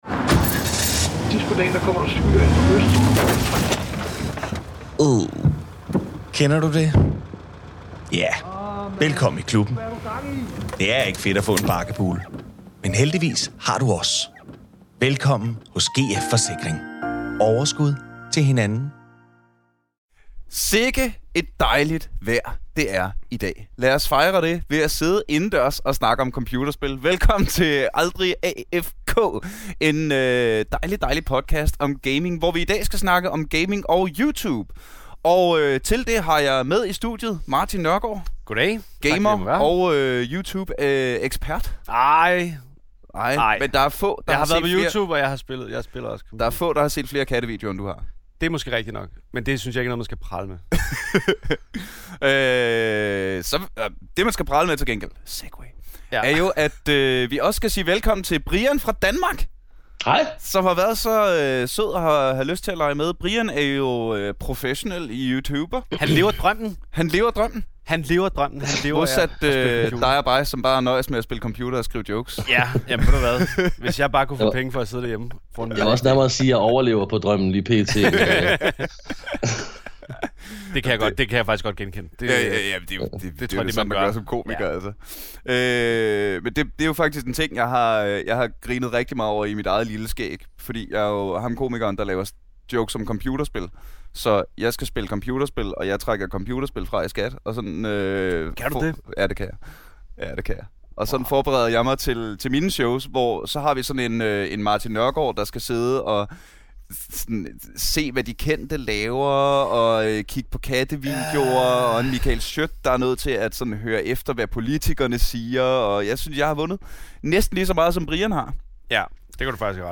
i studiet til en snak om streaming, Click-bait og gaming.